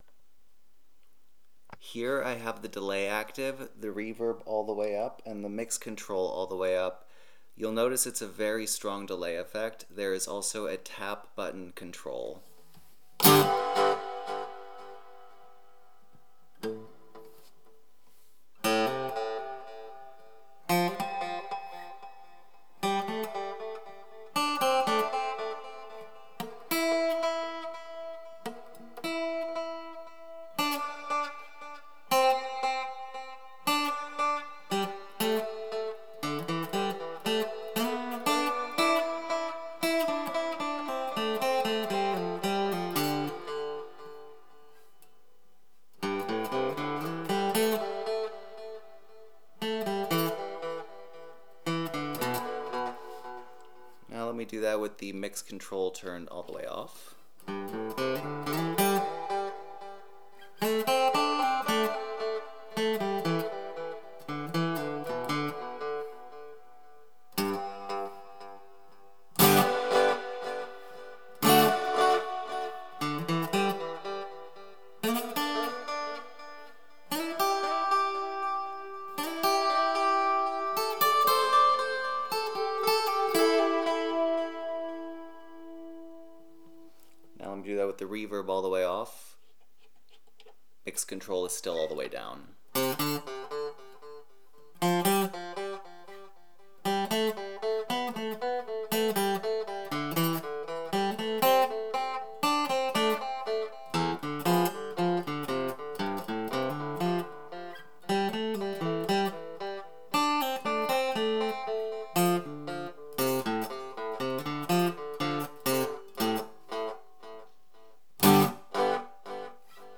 Here are some audio samples of how the guitar sounds acoustically. Both with and without effects. This was recorded using my Olympus LS-100 stereo recorder.
(chorus playing singles notes) – LOUDER, TURN DOWN SPEAKERS/HEADPHONES
(Delay)